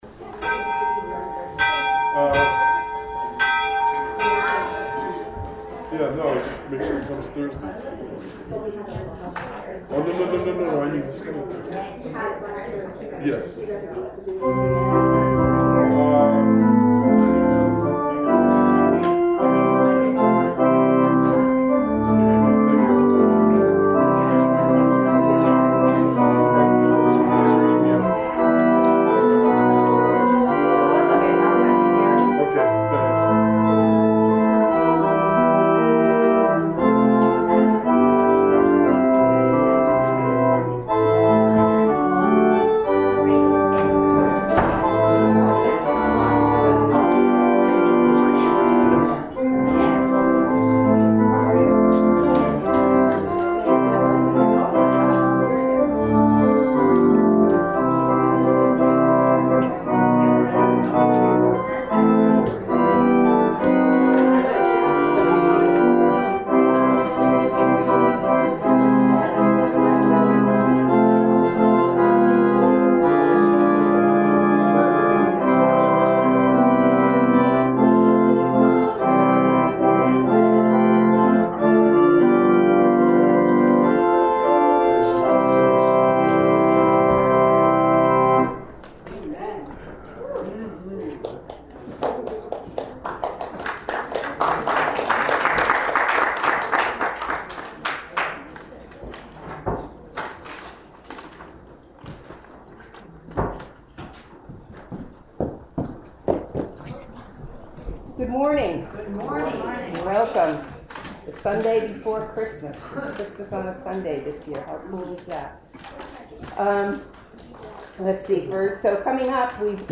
December 24th, 2016 Christmas Eve Service Podcast